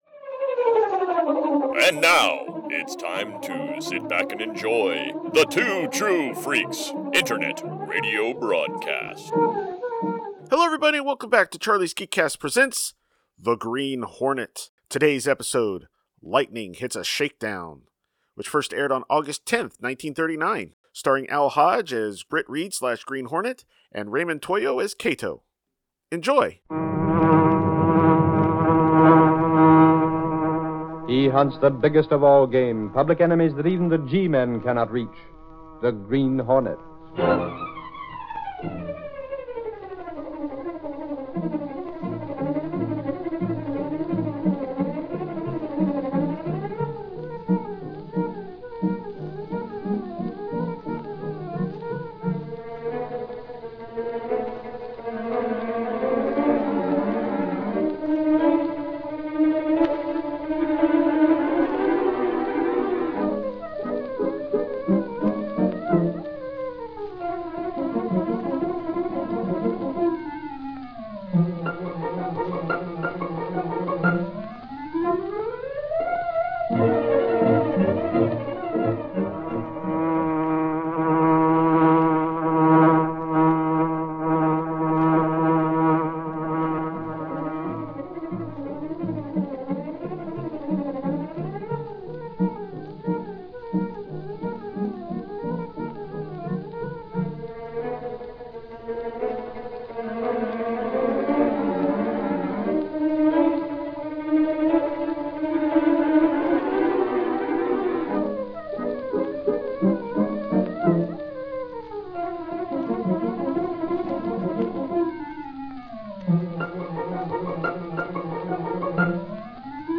See author's posts Tagged as: Kato , The Daily Sentinel , The Black Beauty , radio series , The Green Hornet , Britt Reid . email Rate it 1 2 3 4 5